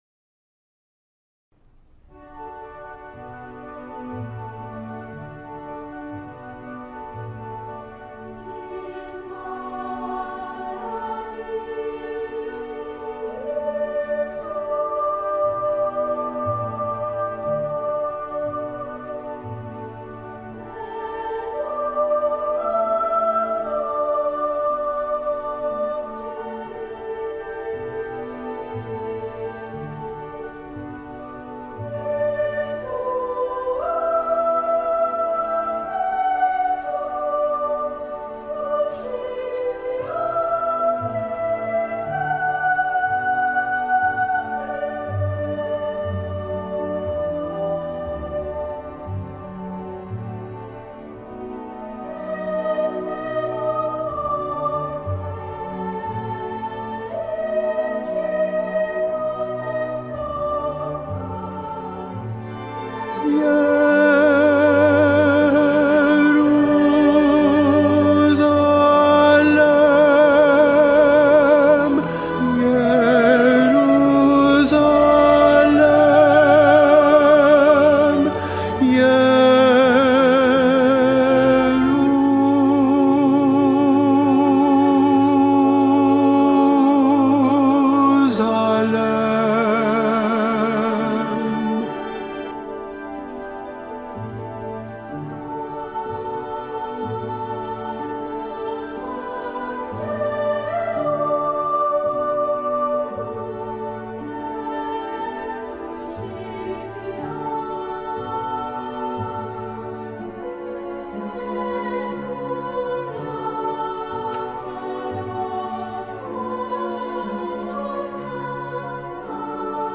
Tenor I
Mp3 Profesor